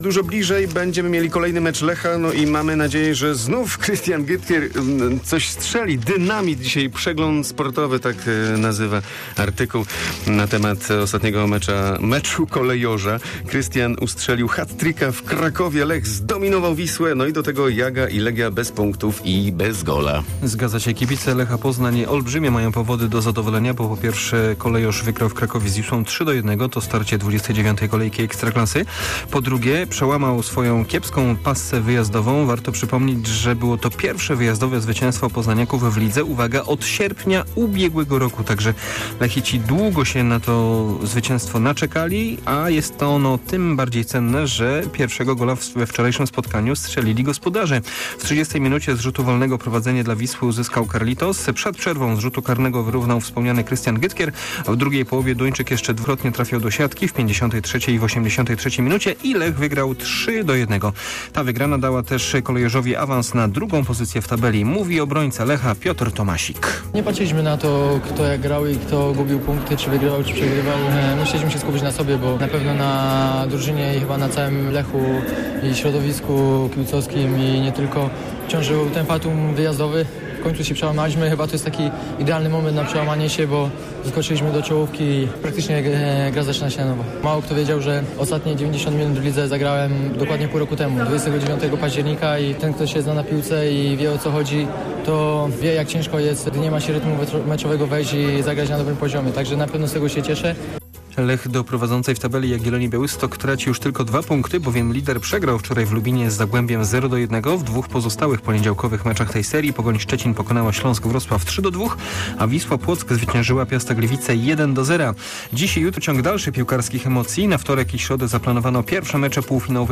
03.04 serwis sportowy godz. 7:45